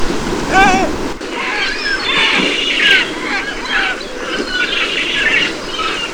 Great Black-backed Gull
Larus marinus